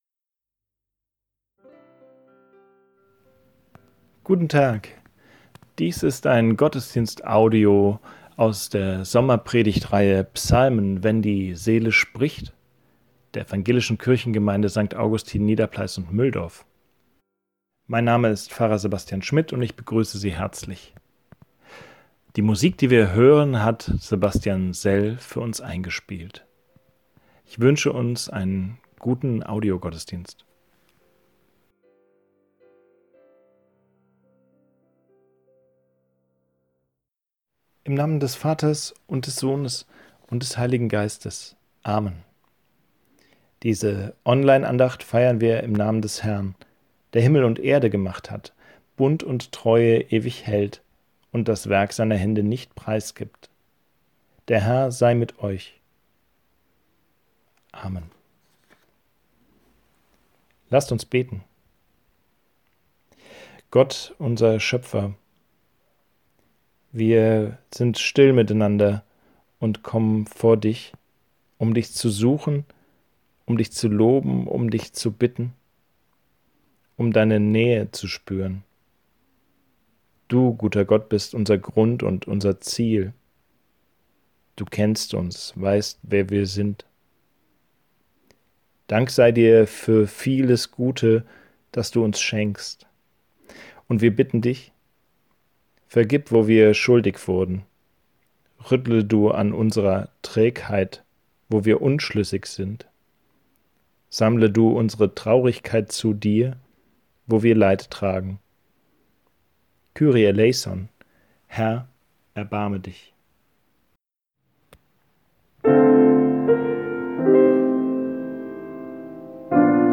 Gottesdienst am 12. Juli 2020 zu Psalm 109